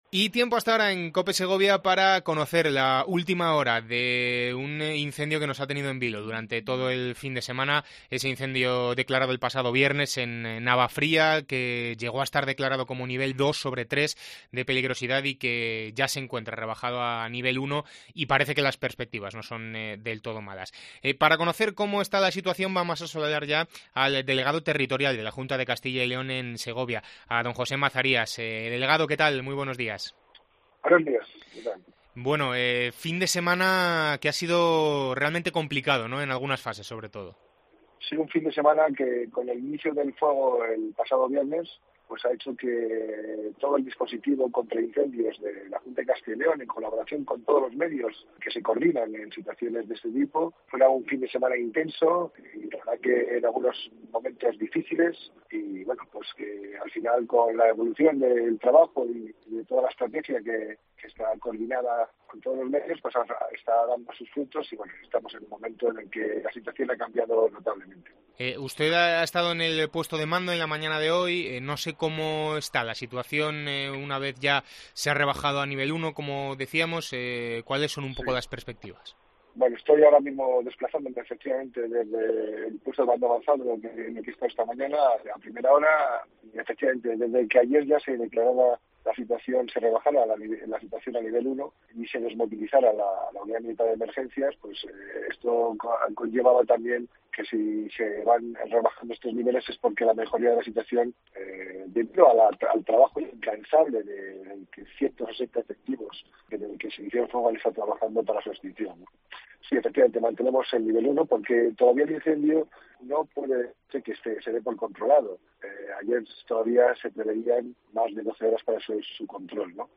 Entrevista con José Mazarias, Delegado Territorial de La Junta de Castilla y León en Segovia